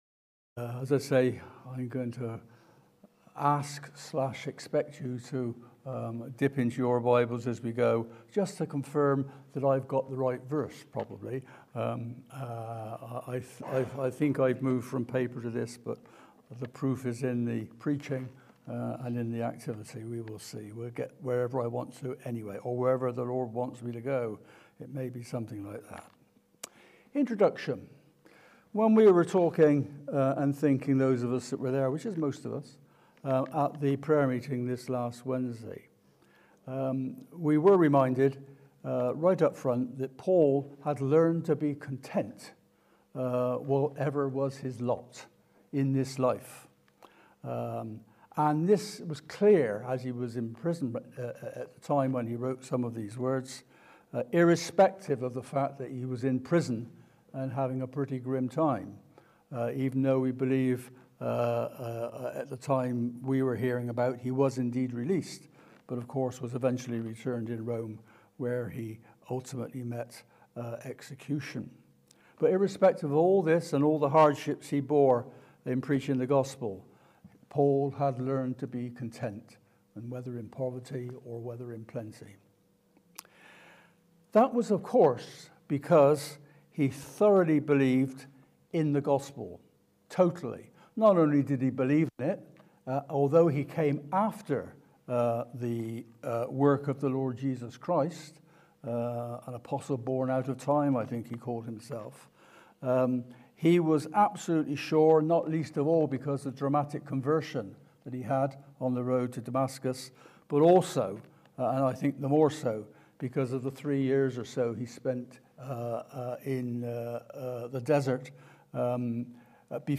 Evening Service This evening we consider the critical topic of forgiveness…